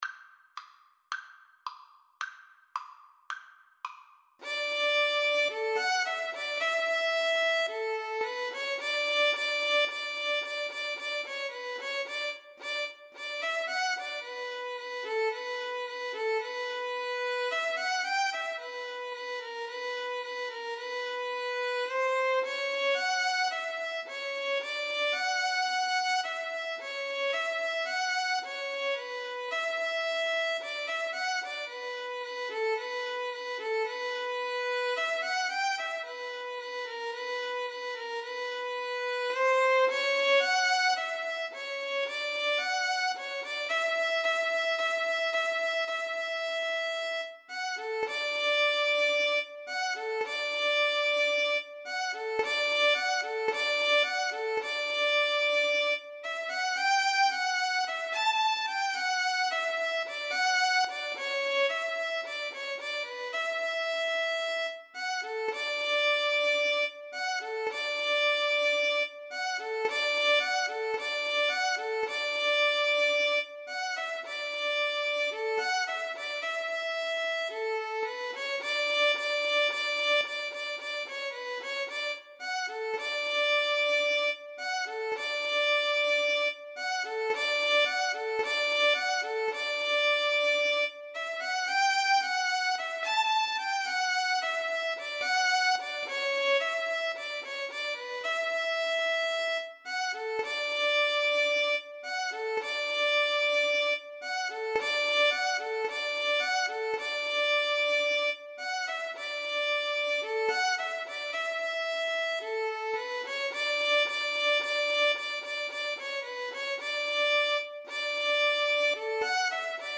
2/4 (View more 2/4 Music)
Moderato allegro =110
Classical (View more Classical Violin Duet Music)